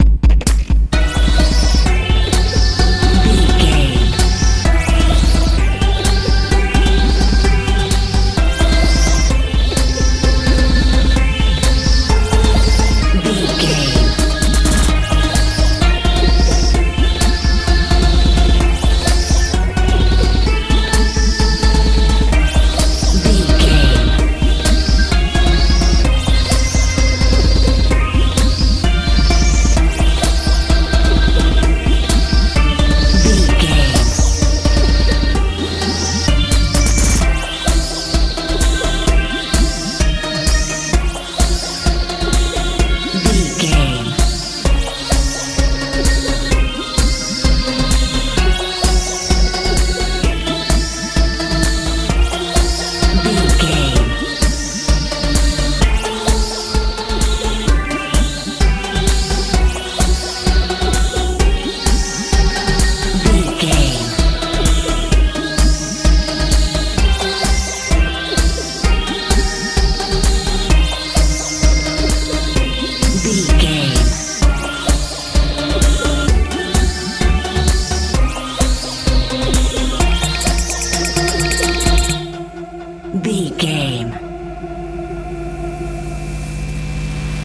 modern dance
Aeolian/Minor
E♭
strange
terror
synthesiser
bass guitar
drums
suspense
tension